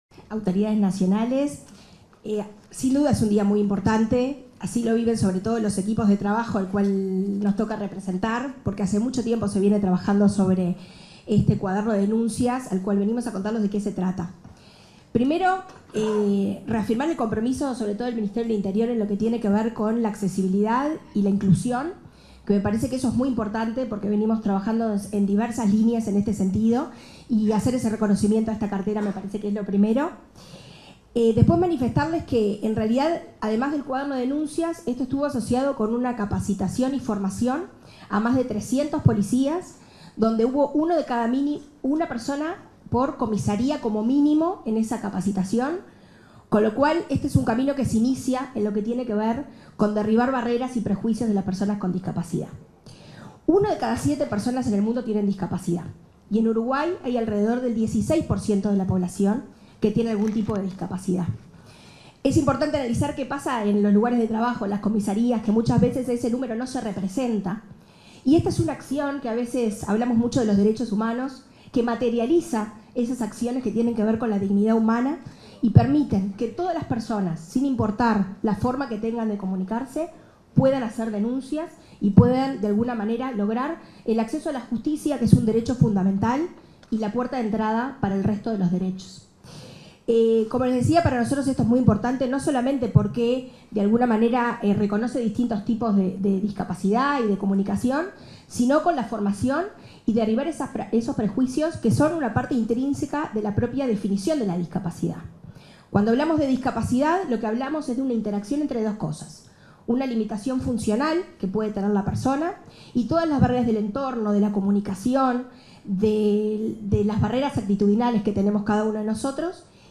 Acto de presentación del Cuaderno de Denuncias Accesible 30/09/2024 Compartir Facebook X Copiar enlace WhatsApp LinkedIn En el marco de la ceremonia de presentación del Cuaderno de Denuncias Accesible, este 30 de setiembre, se expresaron la directora de Discapacidad del Ministerio de Desarrollo Social (Mides), Karen Sass, y el director de Convivencia Ciudadana del Ministerio del Interior, Matías Terra.